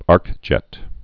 (ärkjĕt)